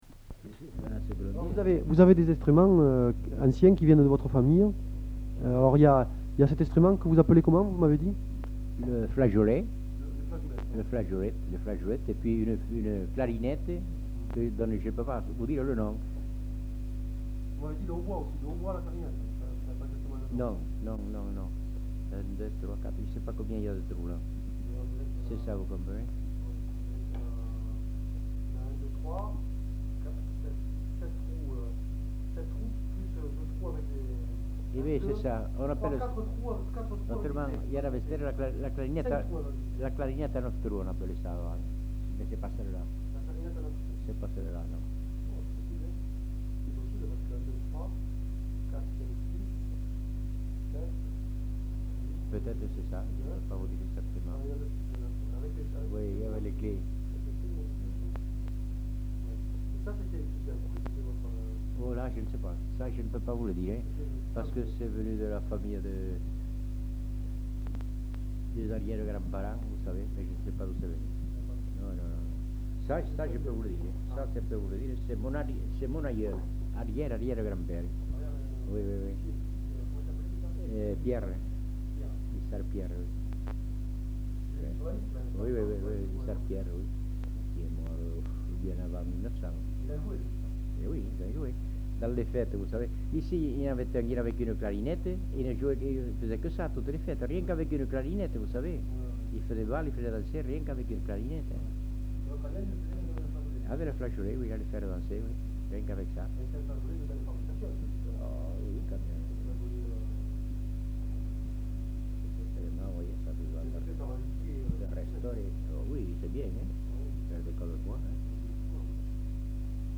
enquêtes sonores
Nature du document : enquête
Type de son : mono
Qualité technique : mauvais ; bruit de fond ; chute de modulation
Résumé : L'informateur, qui au moment de l'enquête fait partie de la chorale du club du 3e âge, entame quelques chants sans aller au-delà des premiers vers. Puis, tout en montrant à l'enquêteur les instruments de musique conservés par sa famille depuis plusieurs générations, il évoque deux musiciens qui, tout seuls, animaient les bals à la clarinette.
Aire culturelle : Lauragais